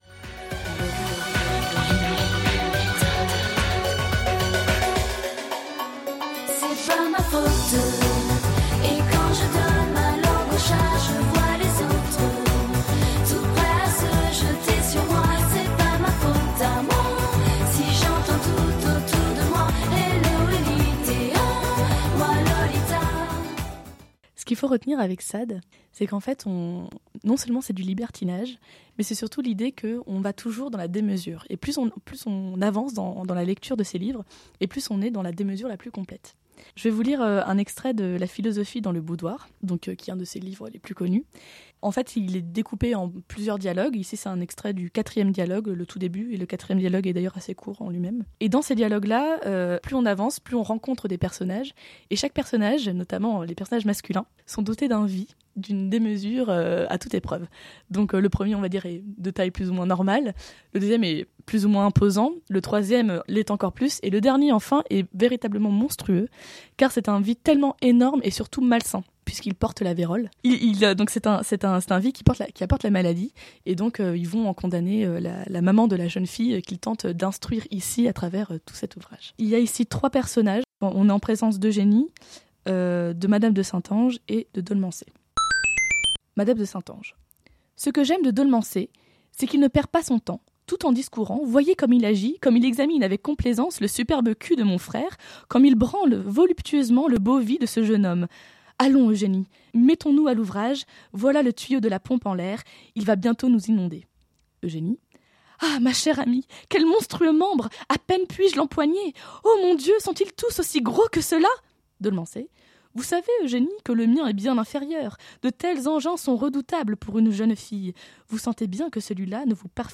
Lectures à voix haute, étonnantes et enjouées.